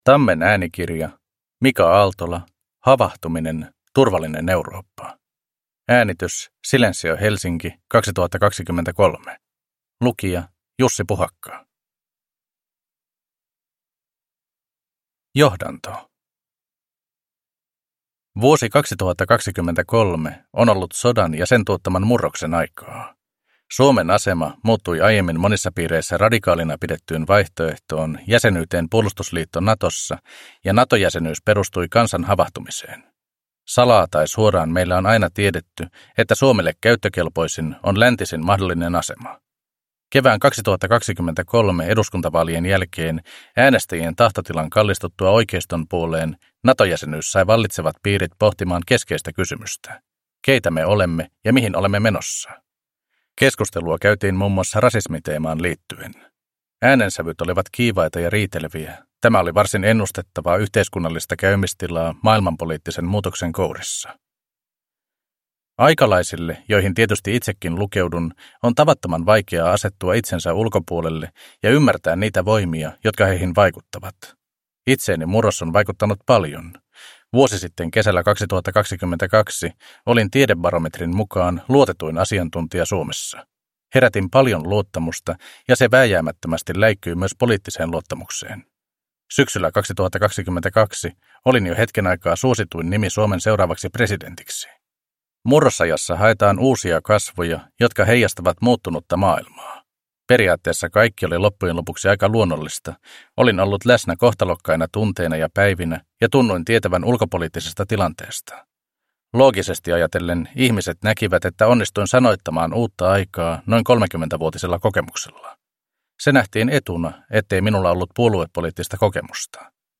Havahtuminen - Turvallinen Eurooppa – Ljudbok – Laddas ner